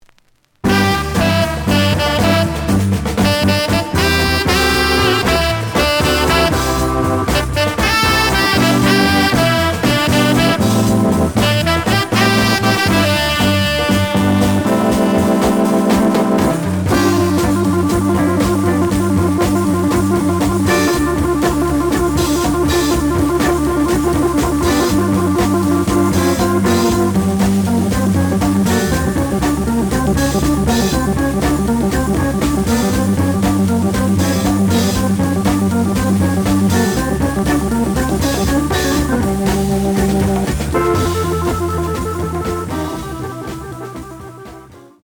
The audio sample is recorded from the actual item.
●Genre: Jazz Funk / Soul Jazz
B side plays good.)